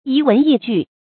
遺文逸句 注音： ㄧˊ ㄨㄣˊ ㄧˋ ㄐㄨˋ 讀音讀法： 意思解釋： 指散佚的文章和句子。